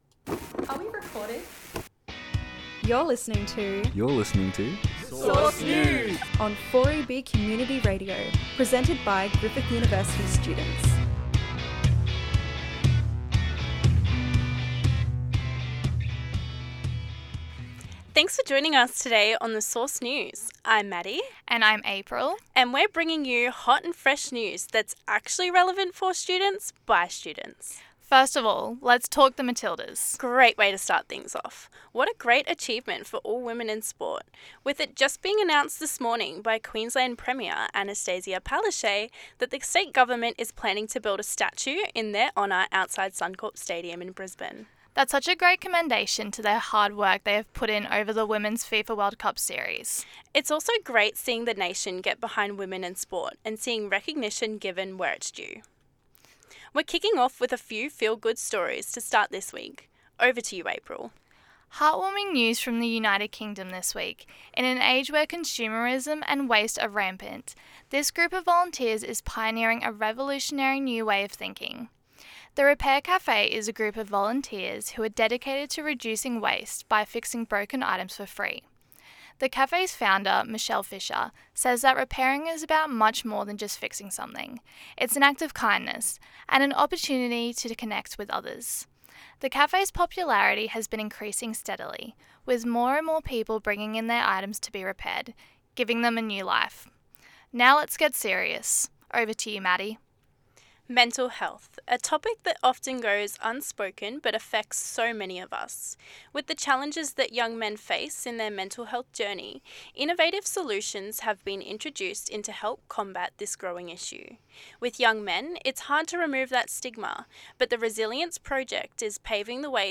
Today on The Source News…We’ll cover how the Resilience Project is working to break the stigmas surrounding young Australian men’s mental health; hear about undergraduate student’s recent international trip as funded by the Department of Foreign Affairs and Trade’s New Colombo Plan (NCP) Mobility Project; uncover the secrets of the contentious rental crisis with interviews from both renters and landlords on their shared struggles and similarities; and we explore the COVID influenced shift in attitude and values in young Australian over the last few years.